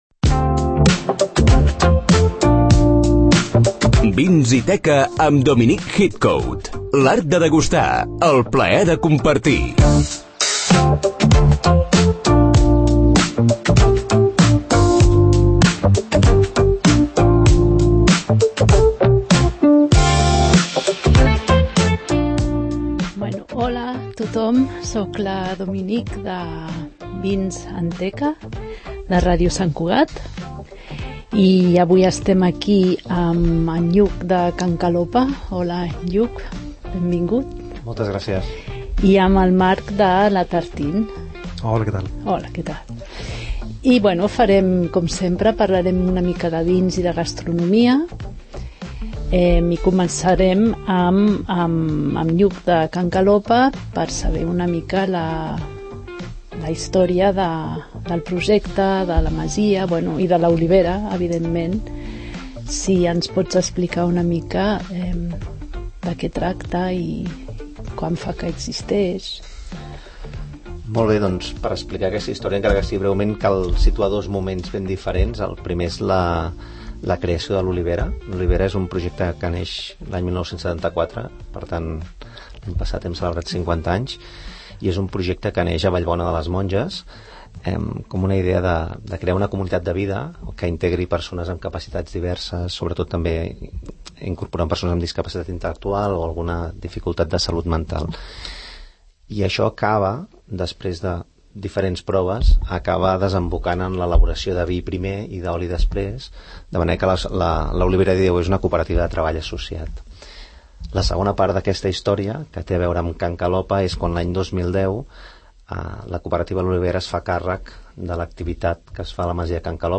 El pòdcast especialitzat en vins i gastronomia ‘Vins & teca’ estrena un nou capítol, amb convidats del sector vitivinícola i de la restauració.
Tot plegat, acompanyat de bona música i moltes ganes de compartir, aprendre i degustar.